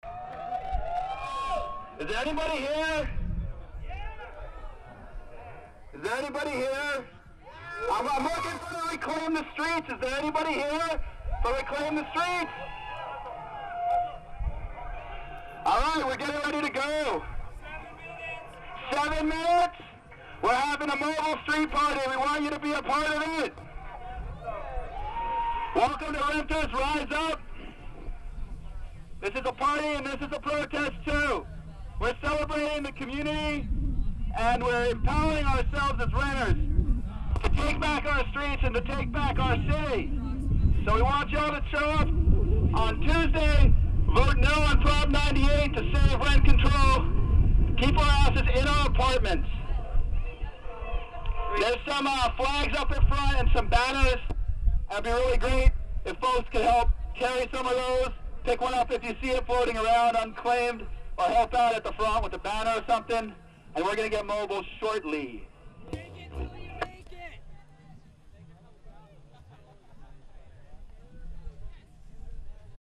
On May 31st, Reclaim the Streets SF reclaimed the streets of the Mission with an unpermitted street party to spread the word "vote NO" on 98, the measure funded by landlords to end rent control in California.
§Sounds from RTS